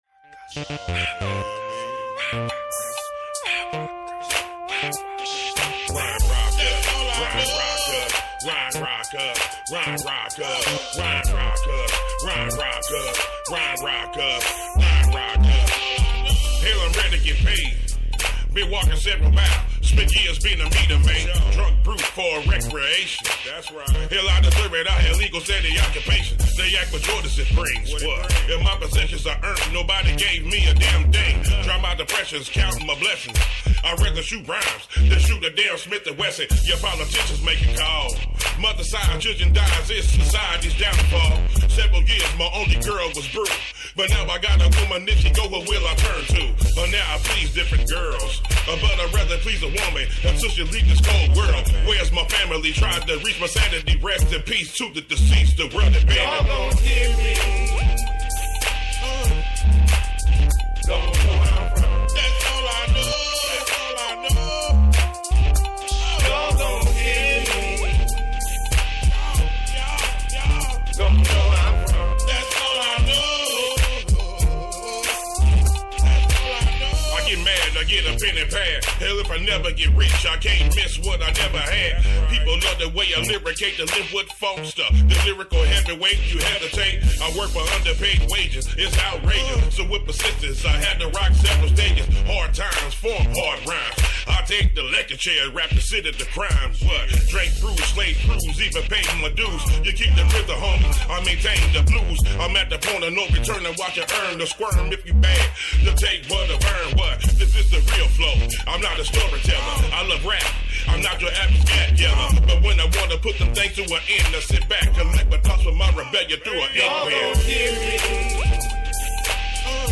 This episode of Operation KickBack aired live on CityHeART Radio on Tuesday June 4, 2024 at 1:15pm.